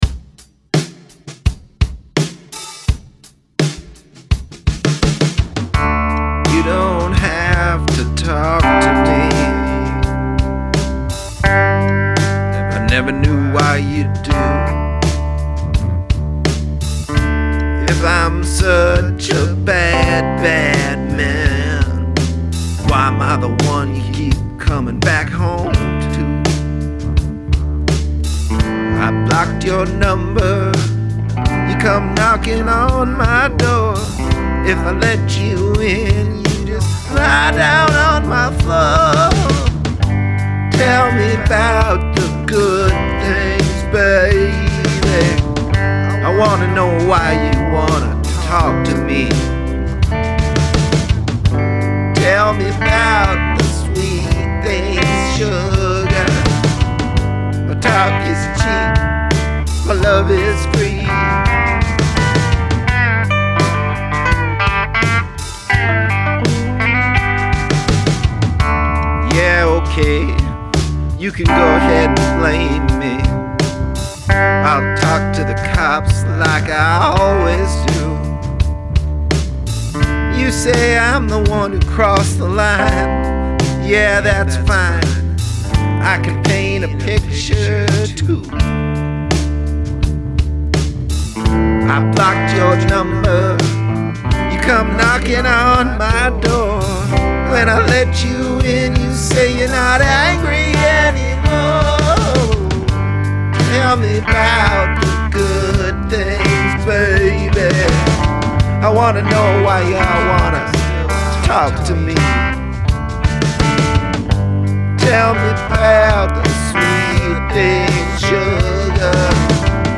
Cigarette rock.
84 BPM